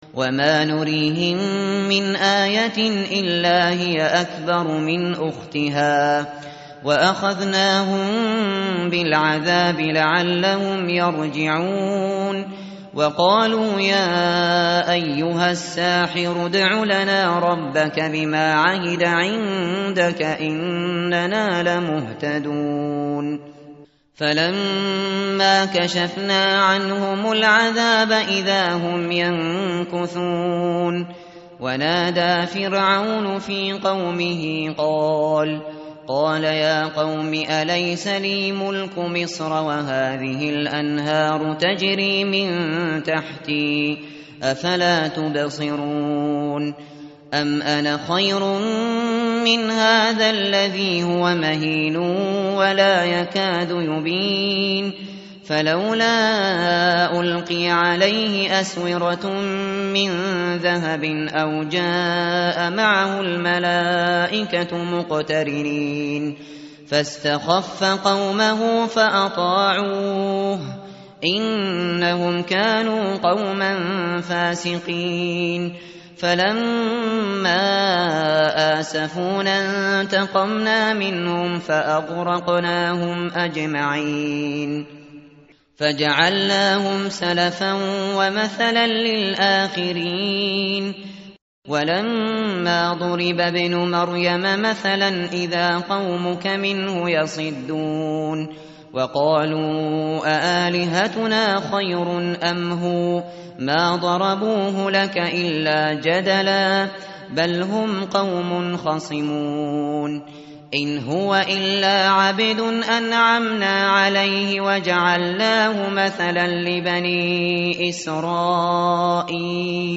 متن قرآن همراه باتلاوت قرآن و ترجمه
tartil_shateri_page_493.mp3